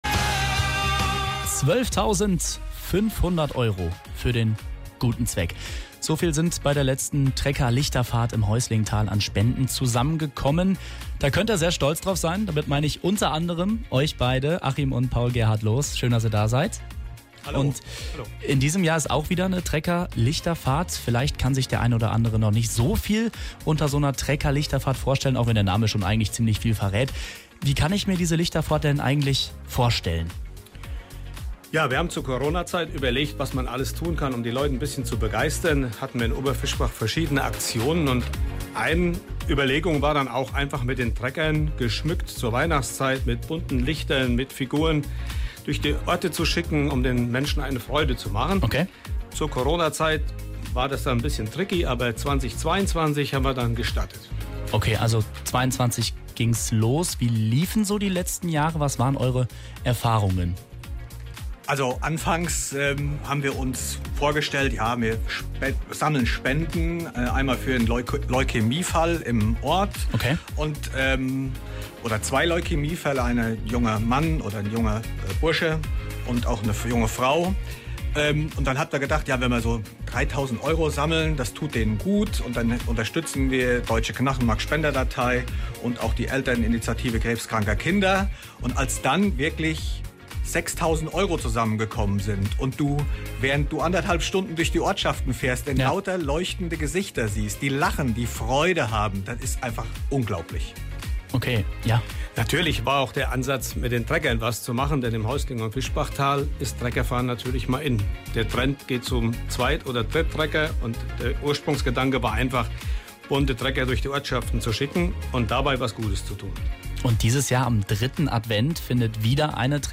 Interview
acs-noel---treckerlichter-im-heuslingtal-interview.mp3